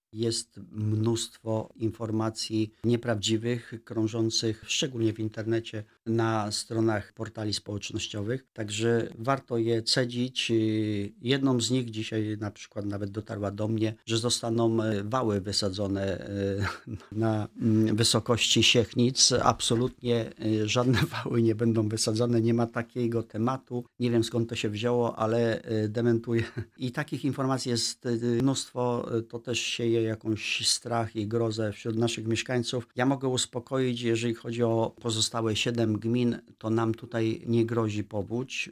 Hieronim Kuryś, czyli członek zarządu powiatu wrocławskiego był w poniedziałek gościem Radia Rodzina.